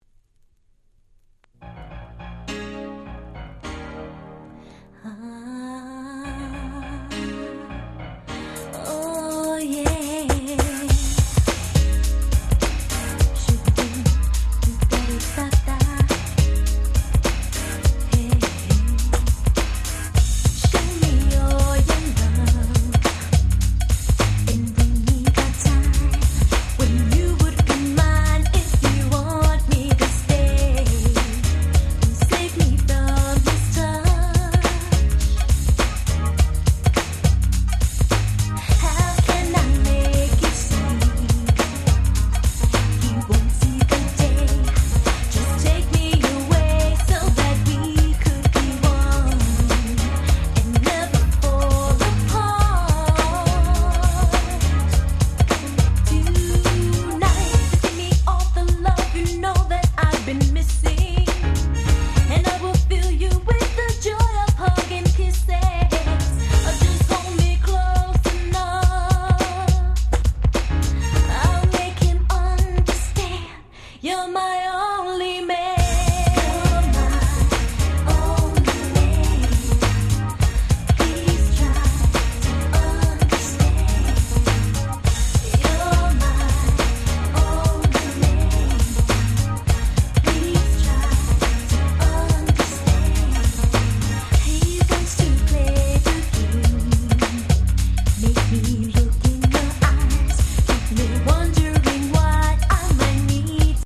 93' Nice R&B !!
詳細は不明ですがB級感溢れる定番BeatのNice R&B !!